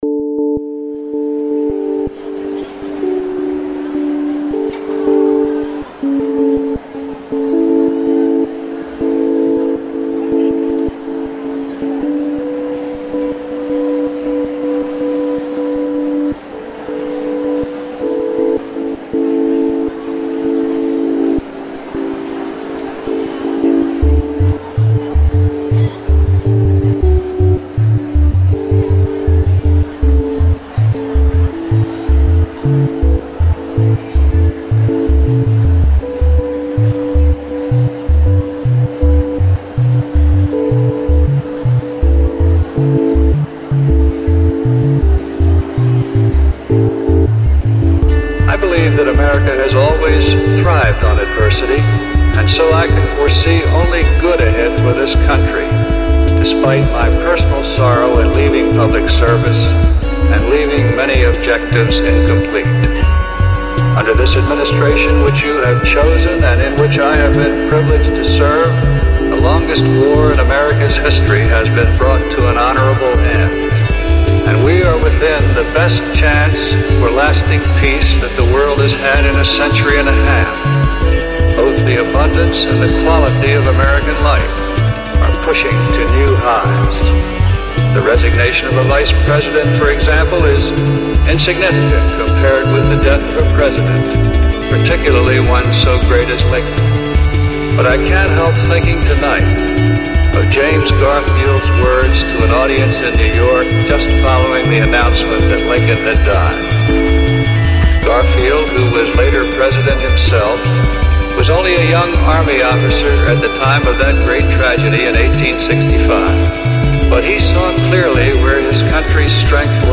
I wanted to do something simple, so I made a simple additive composition in Live 8, starting first with the drumbeat, then the keyboard part, then the bassline.
Lastly, I used a strings preset from Tension, running it also though a Guitar Rig amp, then panned them left and right.
The very last thing I threw on was some crowd noise in an attempt to make the crescendo and subsequent ending feel a bit more... anticlimactic I guess.
The title "God Reigns" is intoned several times within the speech.
Crowd Noise
Filed under: Instrumental | Comments (4)
The background in particular is tranquil and could stand on its own.